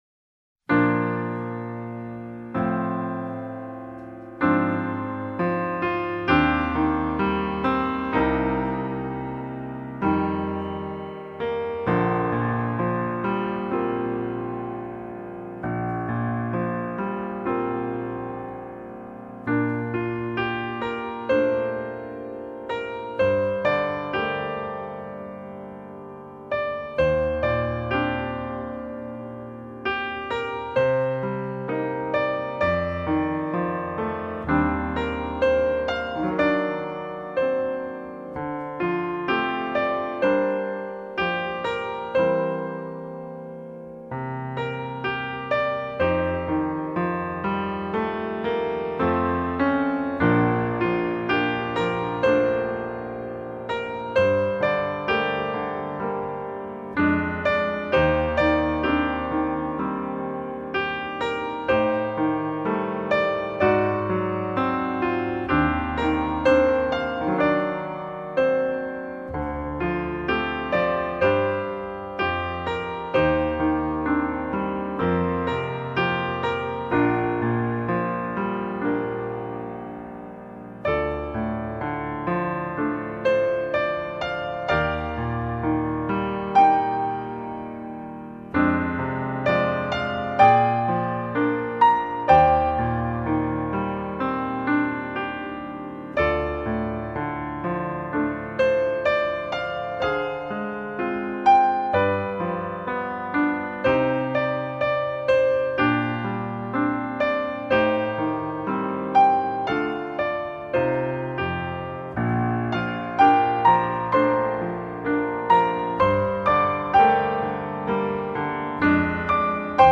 钢琴
也会让周围充满静谧的空气。